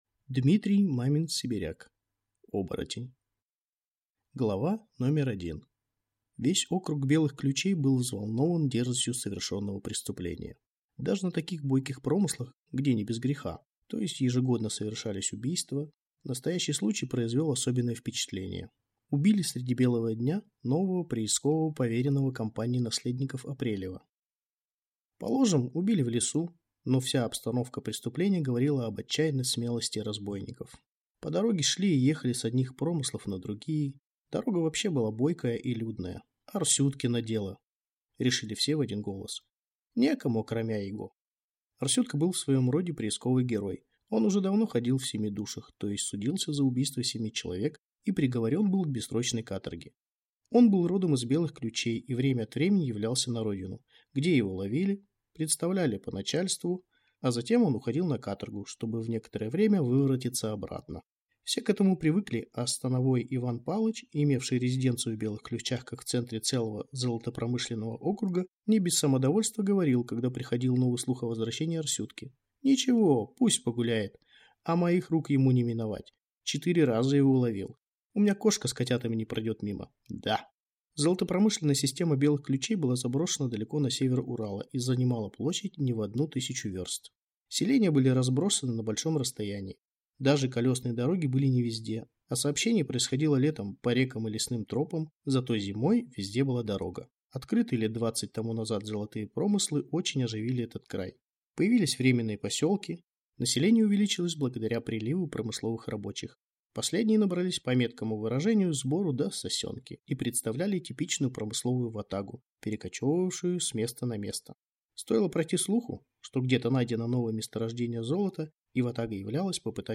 Аудиокнига Оборотень | Библиотека аудиокниг
Прослушать и бесплатно скачать фрагмент аудиокниги